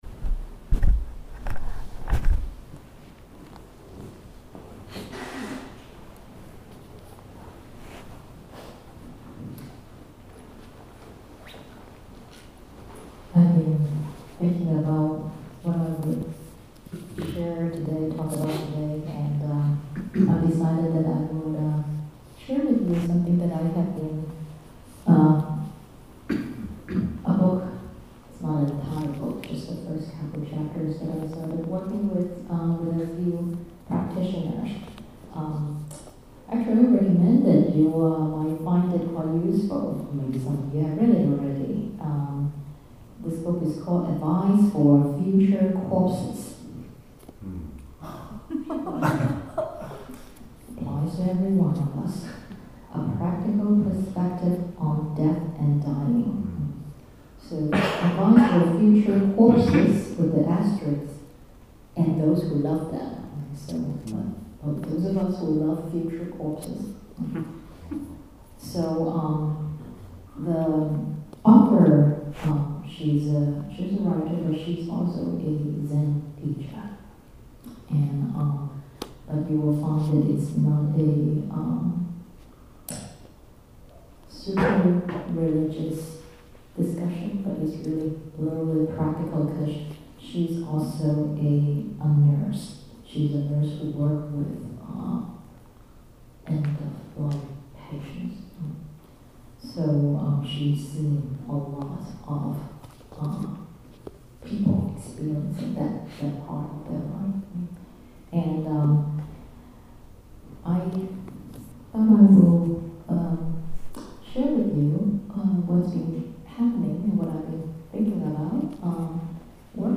This is a reflection on Sallie Tisdale’s book Advice for Future Corpses on how to prepare for our own death and that of our loved ones by engaging in Chan practice. This talk was given as part of the meditation workshop on February 16, 2020 at the New Jersey chapter of Dharma Drum Mountain Buddhist Association in Edison, New Jersey.